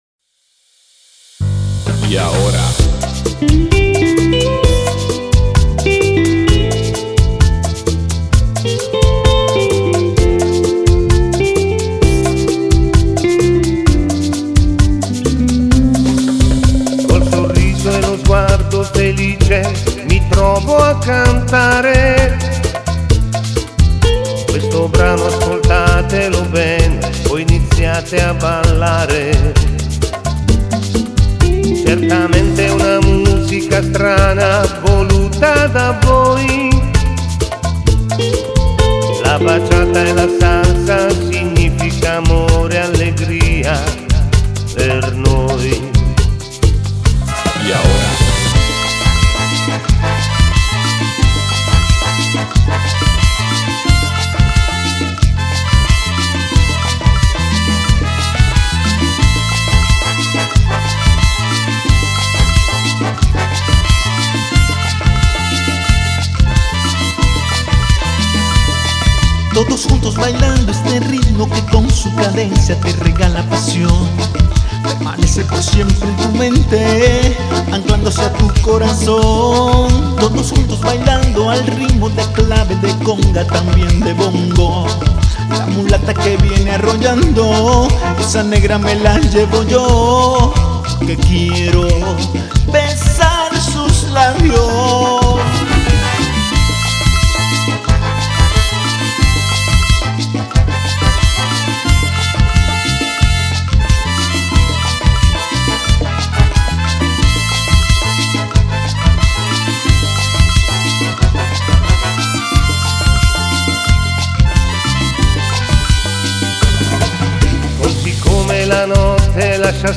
Bachalsa
Ritmo: Bachalsa